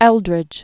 (ĕldrĭj), Roy David Known as "Little Jazz." 1911-1989.